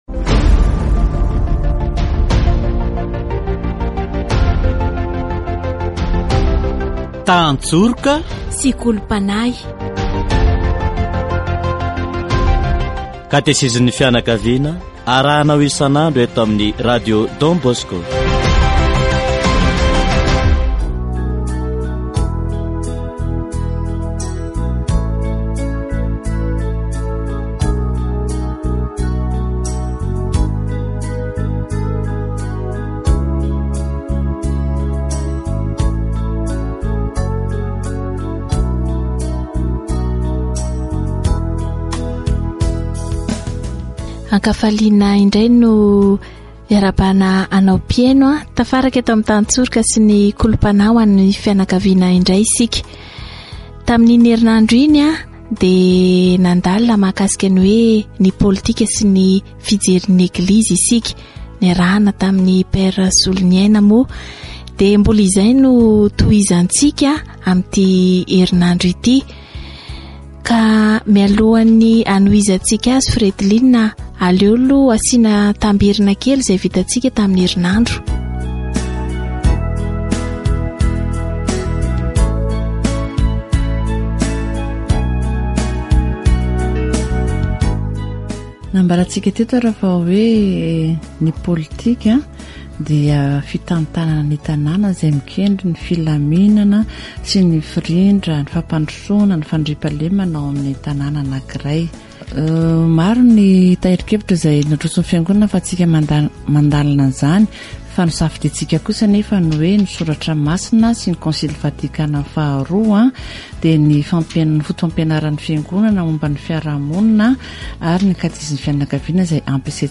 Category: Deepening faith
Catechesis on politics according to the opinion of the Church